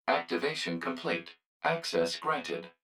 042_Access_Granted.wav